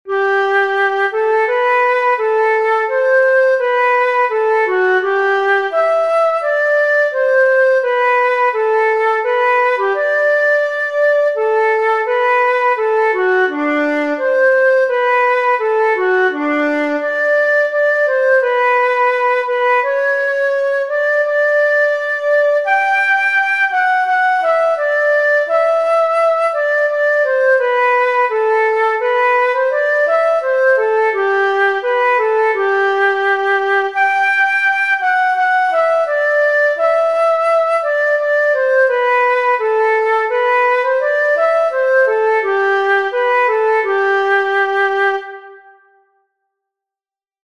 Free Sheet Music for Flute
Its clear structure and flowing lines lend themselves beautifully to the flute’s pure tone, making it suitable for sacred services, contemplative performances, or as a classic example of melodic beauty.